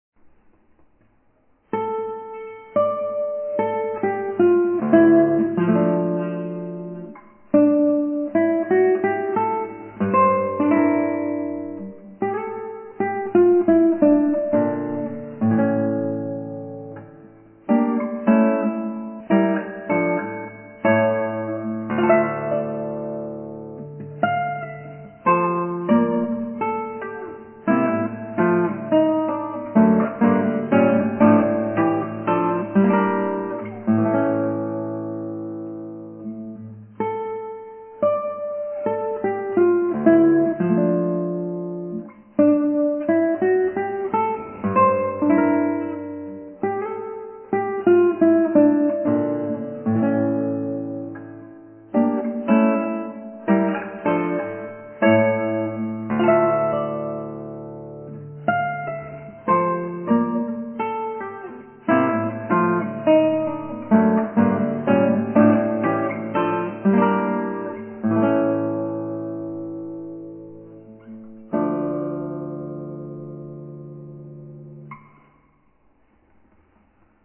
マリンで